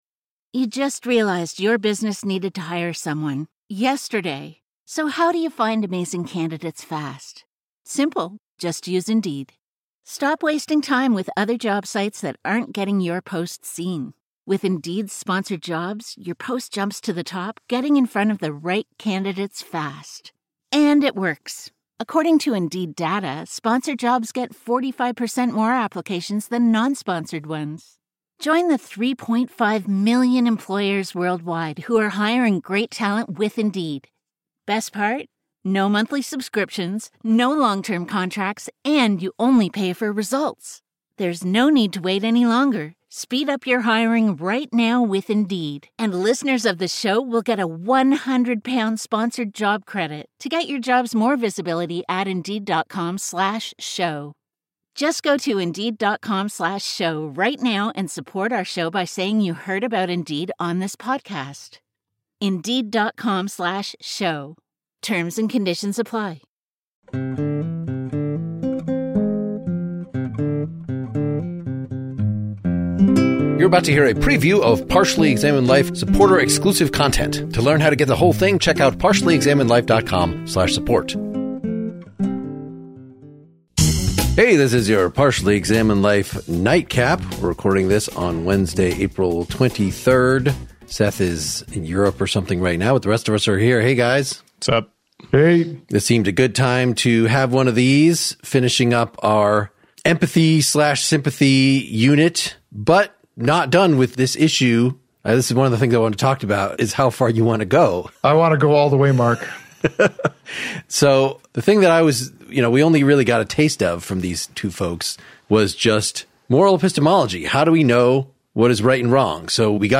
discussion of Shakespeare’s "The Winter’s Tale."